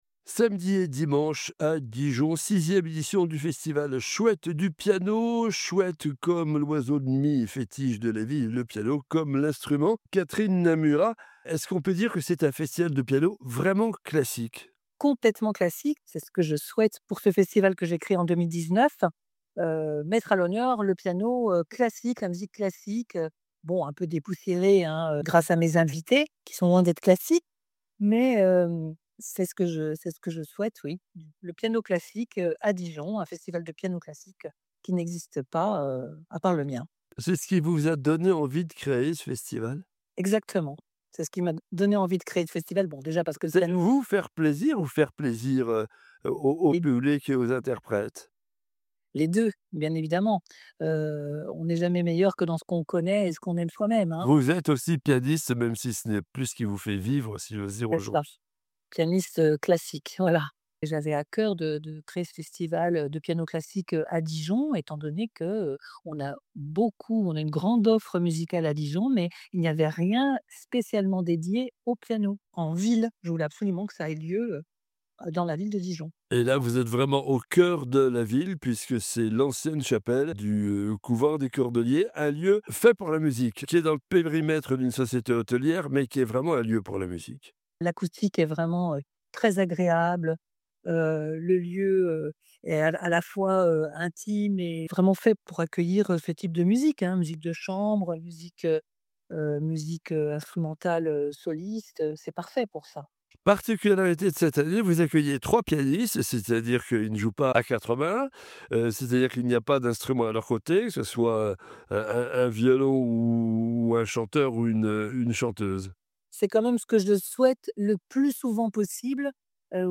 Echange avec la pianiste